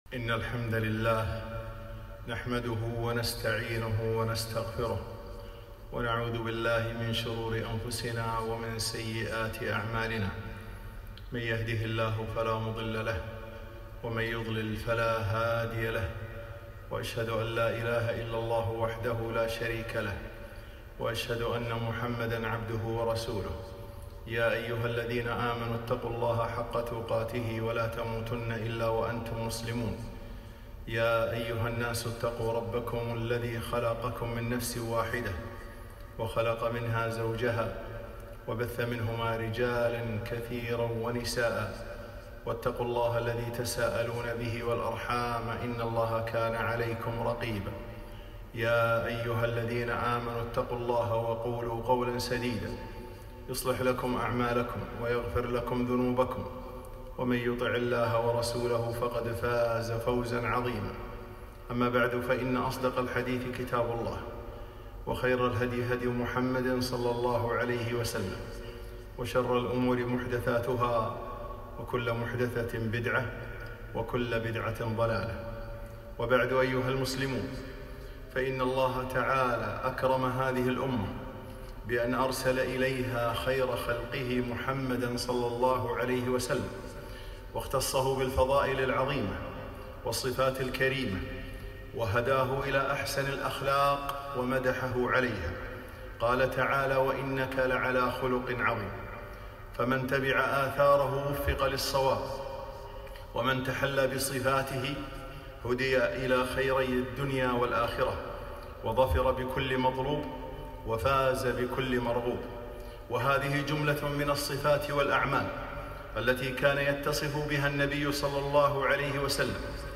خطبة - الاثار النبوية - دروس الكويت